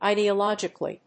音節i・de・o・log・i・cal・ly 発音記号・読み方
/ˌaɪdiʌˈlɑdʒɪkli(米国英語), ˌaɪdi:ʌˈlɑ:dʒɪkli:(英国英語)/